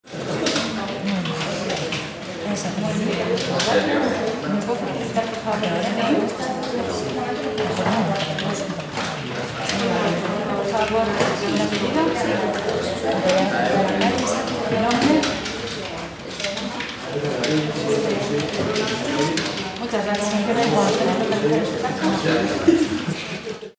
Ambiente interior de una oficina de teleoperadores
Sonidos: Gente
Sonidos: Acciones humanas
Sonidos: Oficina